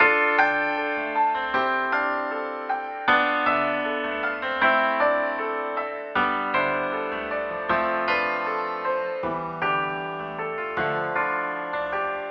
钢琴冲床156
Tag: 156 bpm Trap Loops Piano Loops 2.07 MB wav Key : D Cubase